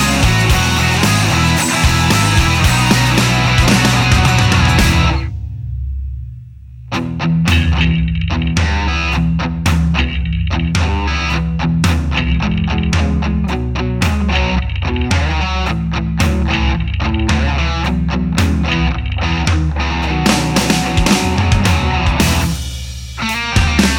no Backing Vocals Indie / Alternative 2:42 Buy £1.50